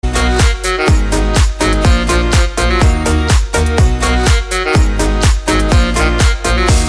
• Качество: 256, Stereo
поп
dance
спокойные
без слов
Саксофон